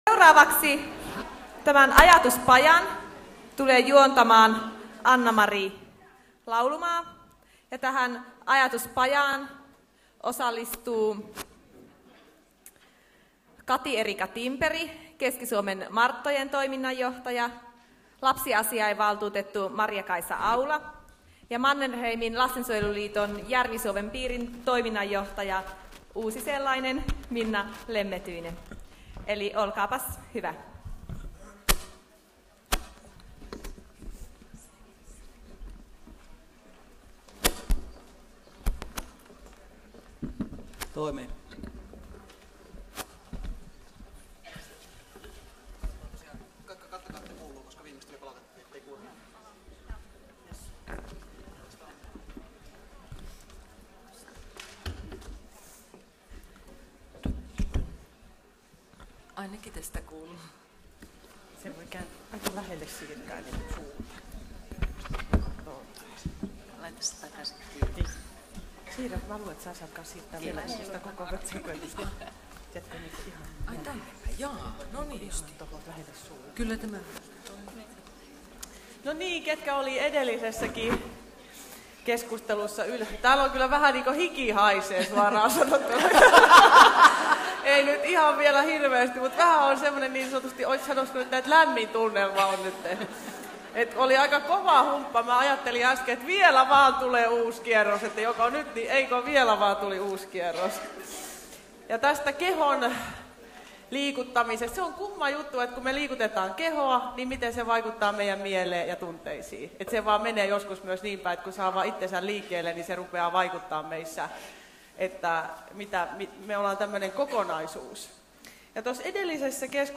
Ajatuspaja